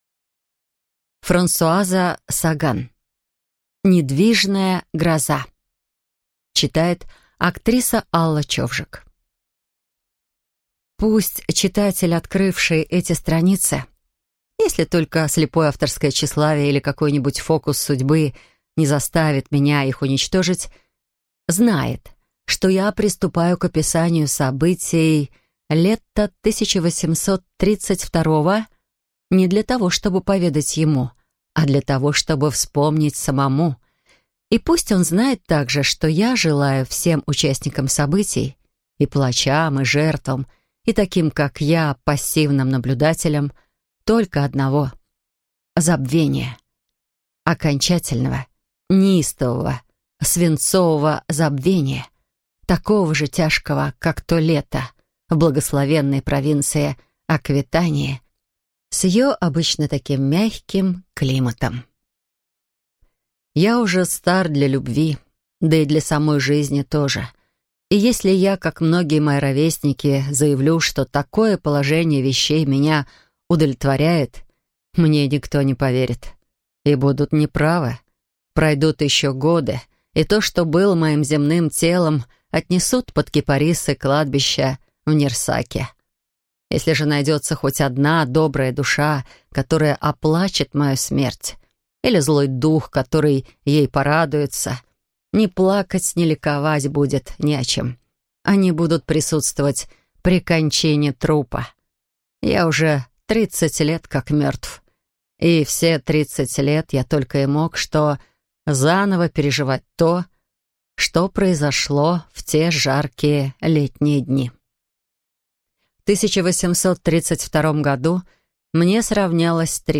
Аудиокнига Недвижная гроза | Библиотека аудиокниг